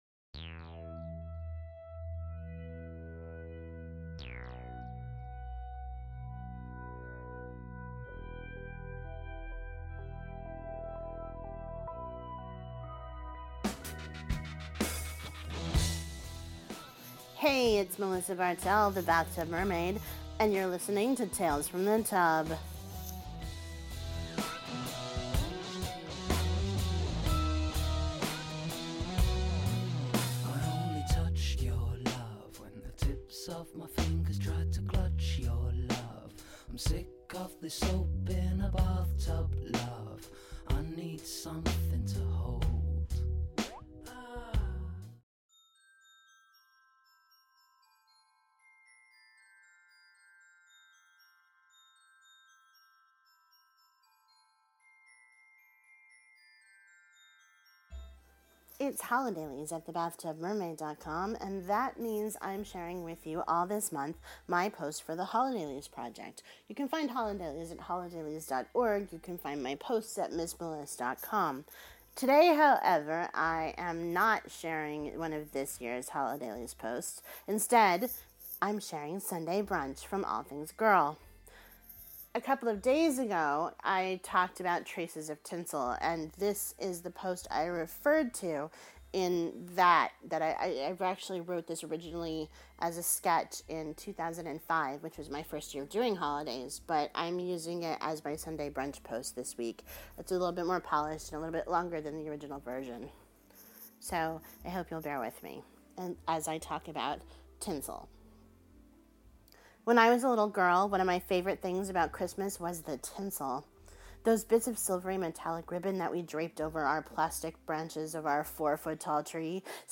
Music for The Bathtub Mermaid is provided by Mevio’s Music Alley, a great resource for podsafe music.